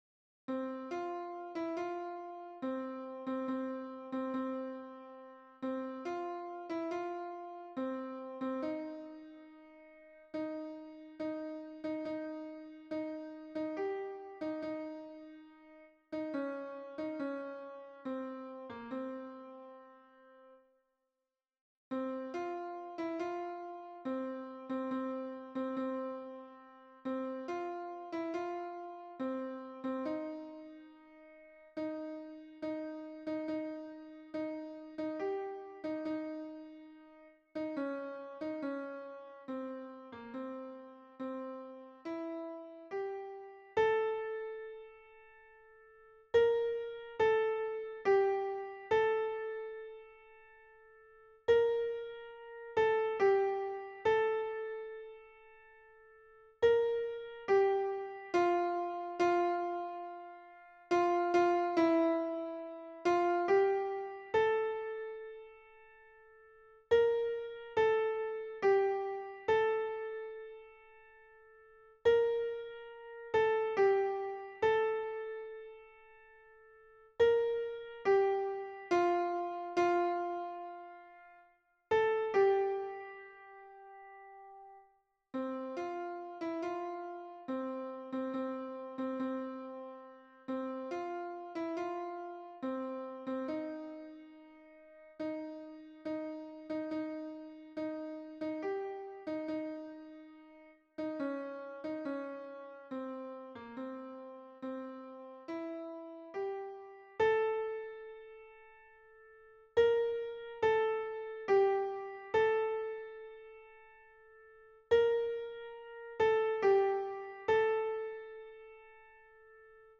MP3 version piano
Alto